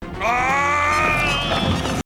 victory shout. Bison makes the exact same noise a little later, when using his psycho crusher.
zangief bison noise.mp3